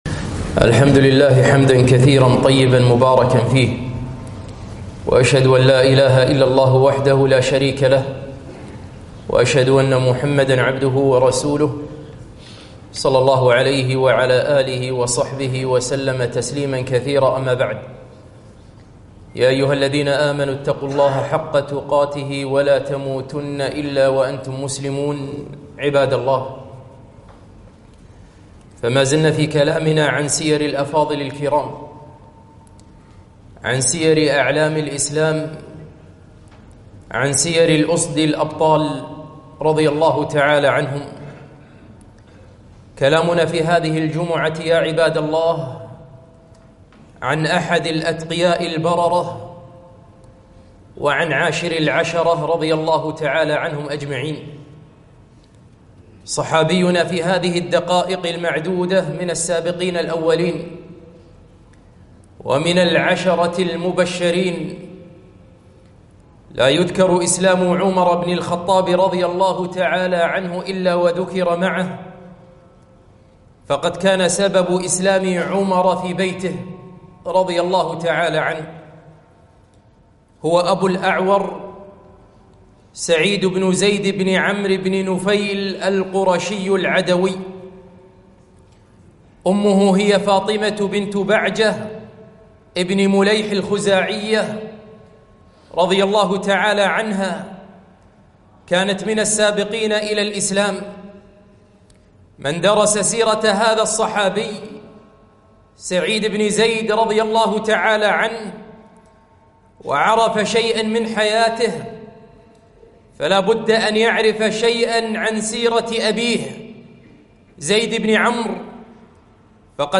خطبة - عاشر العشرة سعيد بن زيد رضي الله عنه